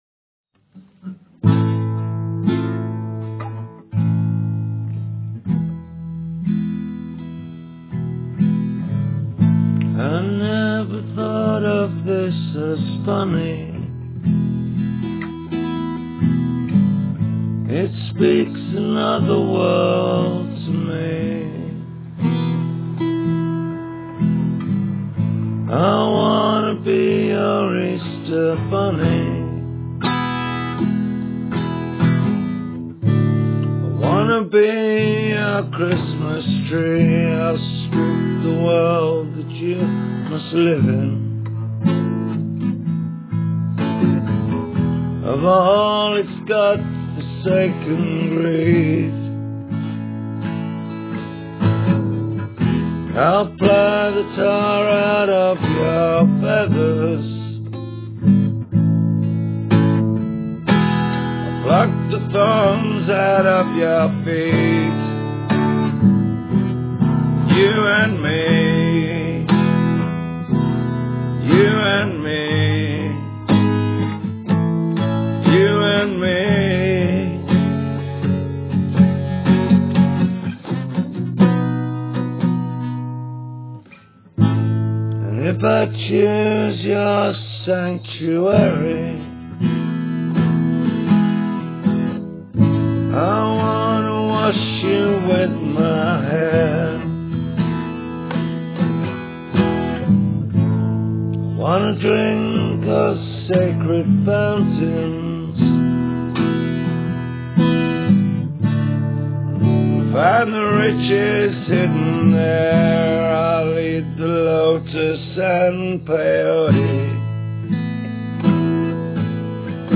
its always mum Clean One Take…
slowed down to a dirge
the guitar reaching tentatively for the next chord.